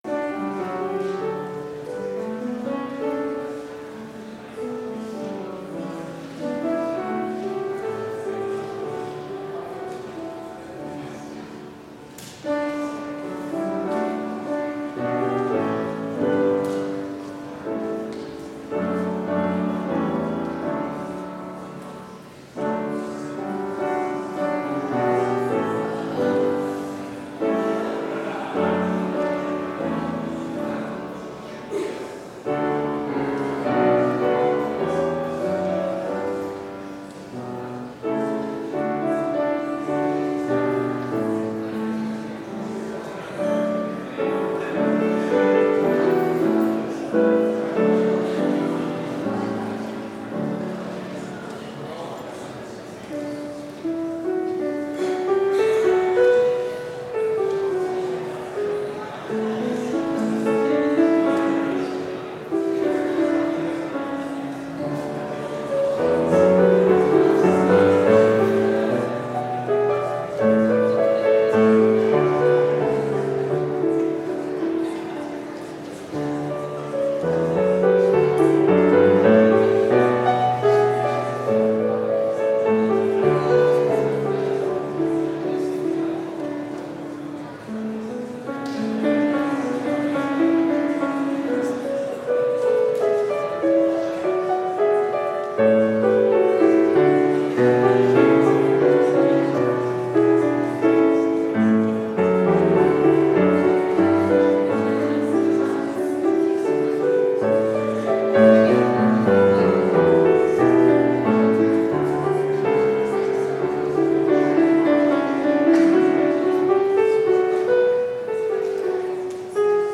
Complete service audio for Chapel - January 17, 2020